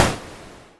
玩具枪
描述：玩具电动枪。
标签： 喷枪 玩具 武器 枪击 拍摄
声道立体声